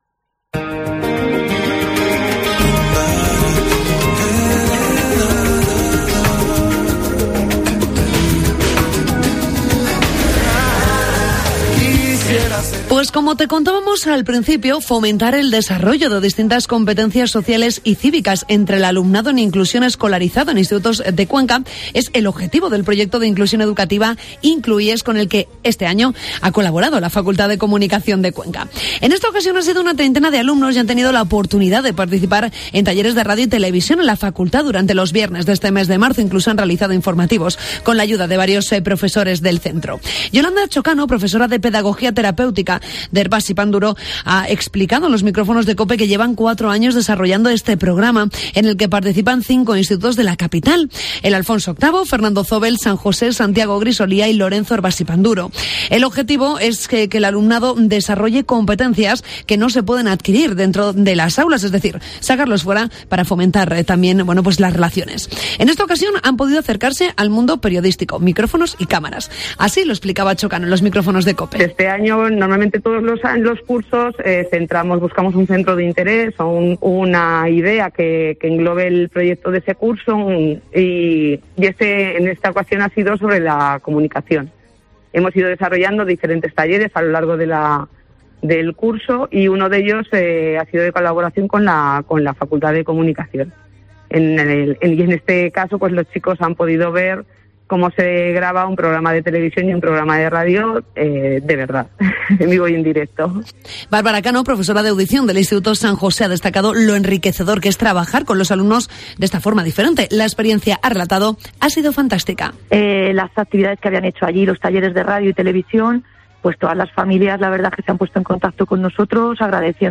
Reportaje proyecto Inlu-IES en la Facultad de Comunicación